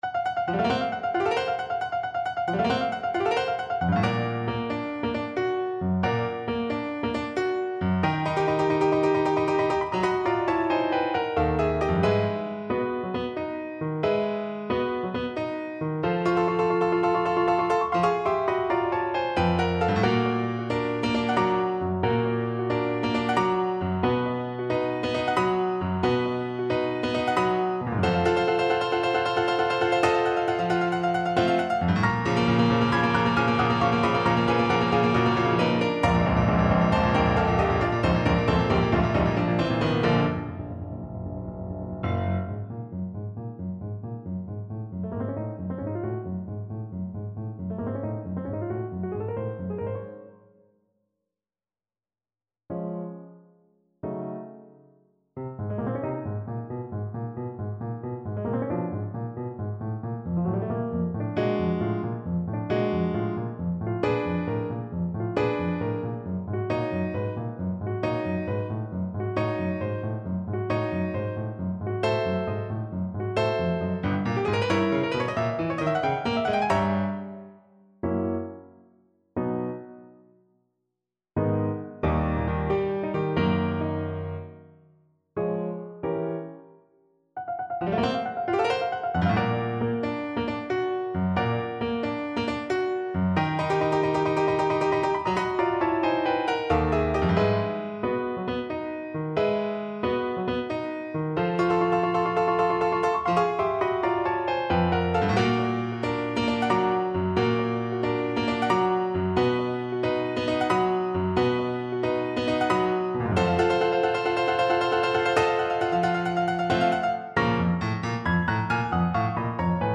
Allegro . = 90 (View more music marked Allegro)
9/8 (View more 9/8 Music)
D5-B6
Classical (View more Classical Clarinet Music)